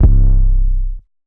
808 [ Dro ].wav